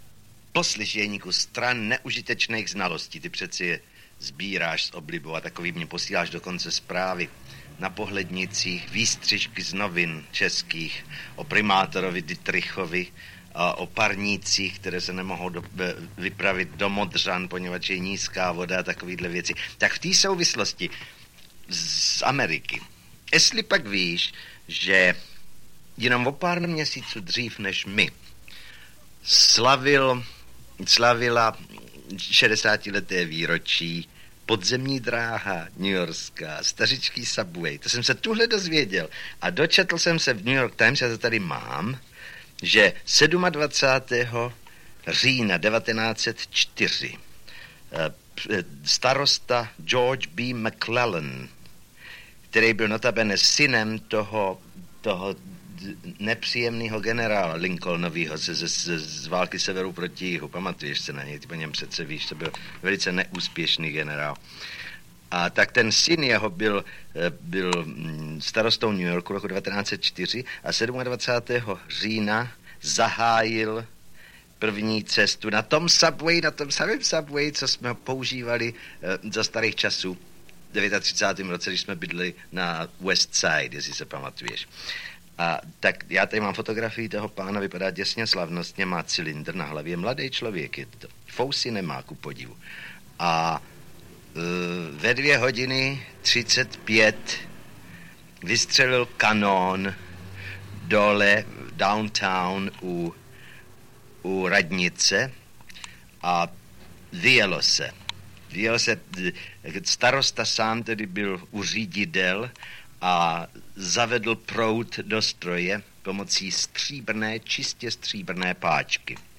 K výročí narození Jiřího Voskovce (19. června 1905) vychází soubor zvukových dopisů, posílaných životnímu příteli Janu Werichovi do Prahy v šedesátých a sedmdesátých letech
Audio kniha
Ukázka z knihy
• InterpretJan Werich, Jiří Voskovec